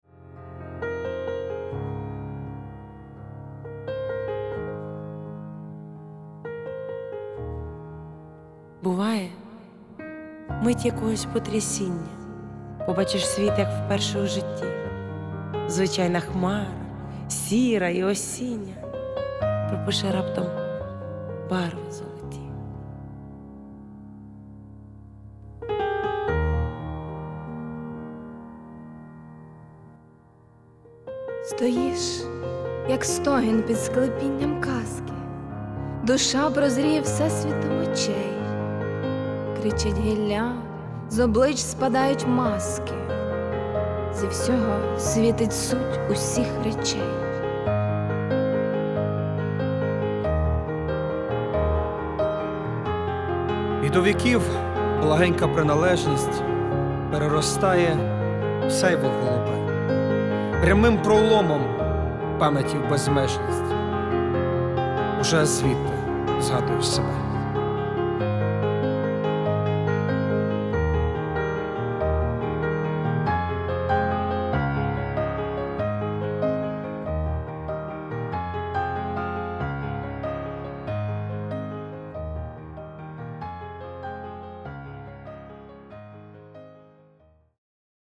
Гарно поєднались геніальна поезія, геніальна музика і ваші приємні голоси give_rose